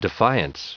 Prononciation du mot defiance en anglais (fichier audio)
Prononciation du mot : defiance